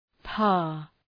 Προφορά
{pɑ:r}